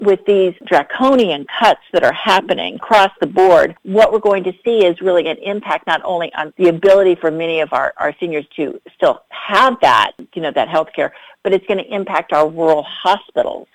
Maryland 6th District Congresswoman April McClain Delaney spoke exclusively with WCBC Thursday about the recently passed budget reconciliation package. She called the cuts a “tsunami” that would affect families, seniors and farmers in Allegany and Garrett Counties.